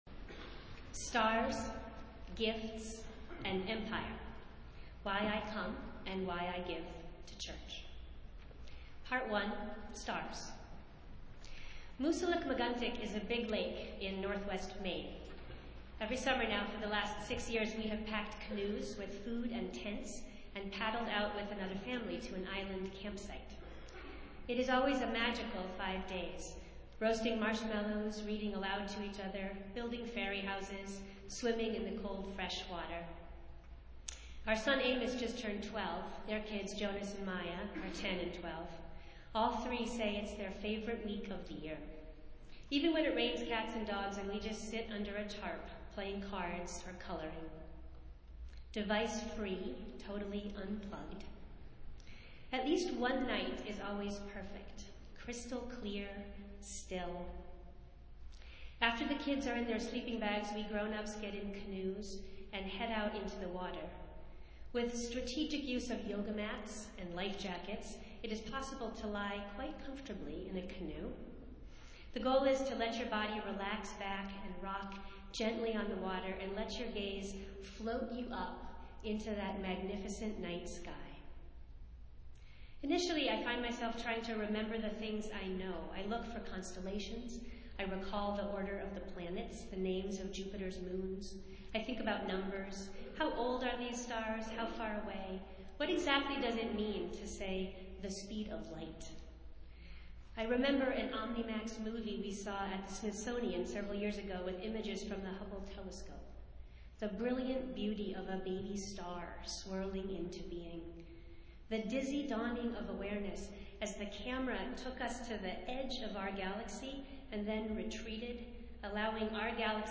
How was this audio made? Festival Worship - First Sunday after Epiphany